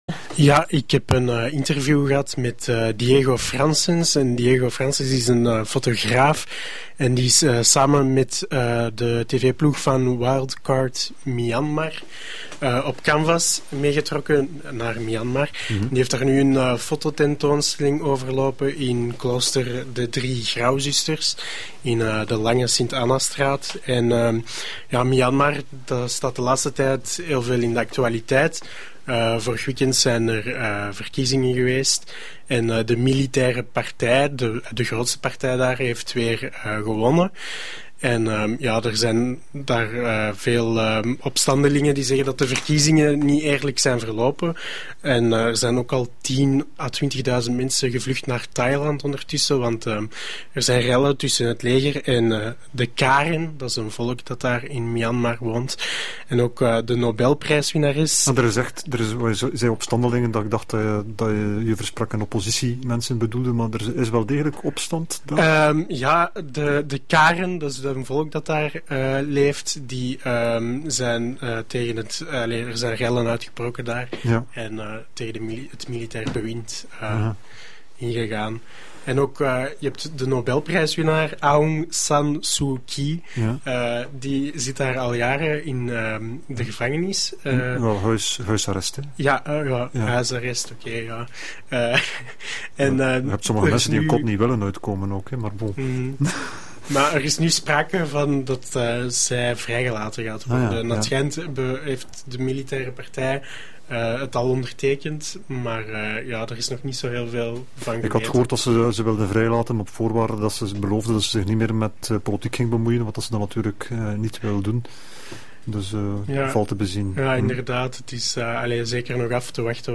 De reportage werd gemaakt voor de verkiezingen. En de reportage werd uitgezonden voor bekend werd gemaakt dat Nobelprijswinnares Aung San Suu Kyi werd vrijgelaten.